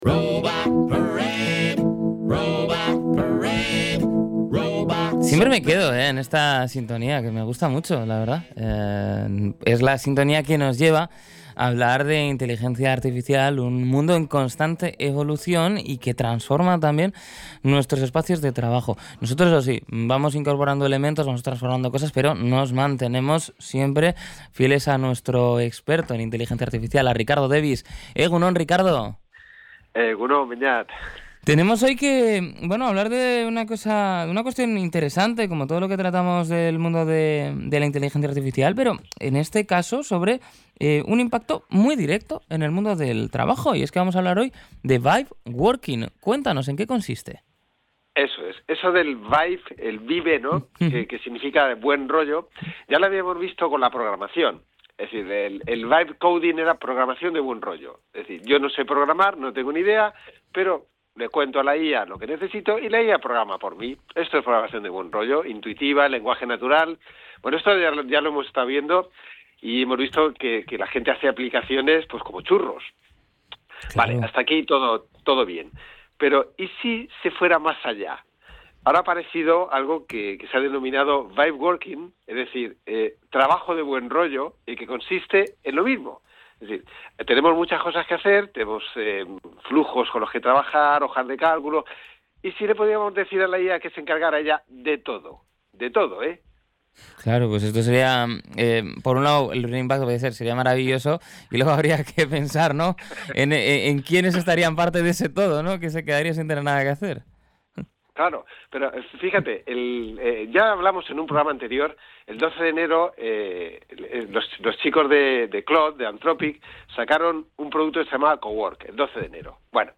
Podcast Tecnología